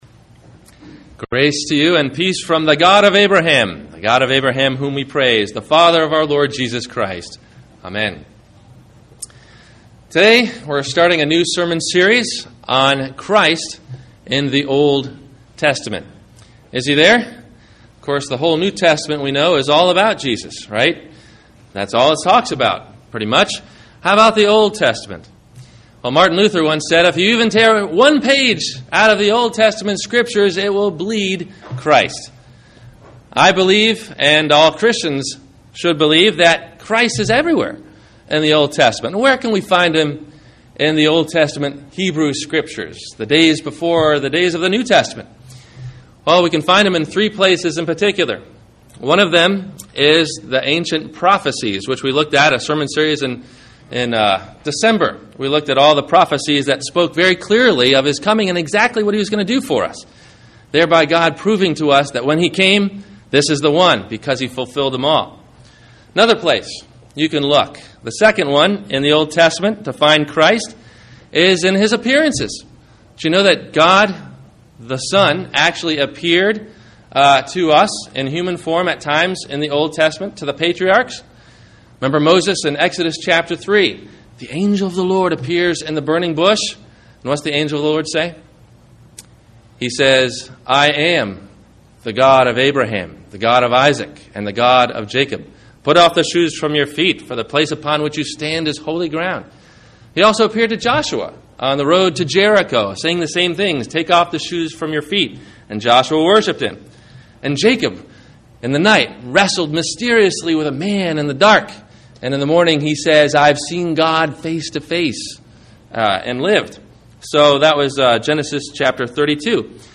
When God Doesn’t Make Sense – Sermon – February 20 2011